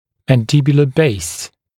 [ˌmæn’dɪbjulə beɪs][ˌмэн’дибйулэ бэйс]основание нижней челюсти